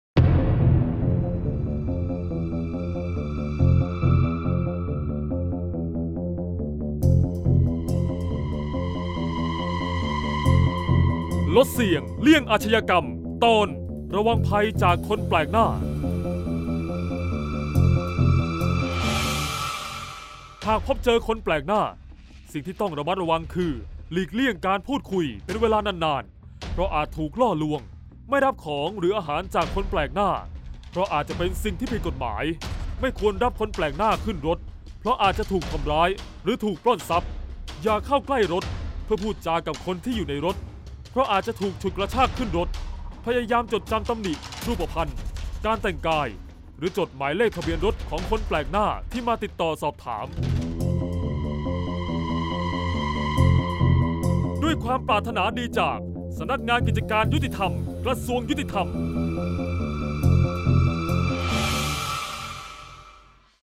เสียงบรรยาย ลดเสี่ยงเลี่ยงอาชญากรรม 39-ระวังภัยคนแปลกหน้า